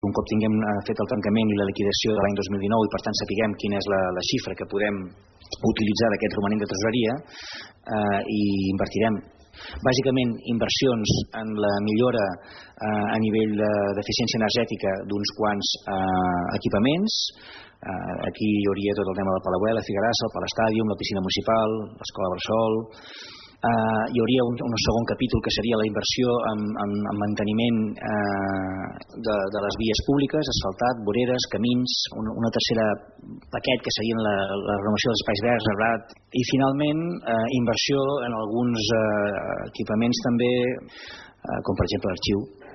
alcalde-inversionsssasd.mp3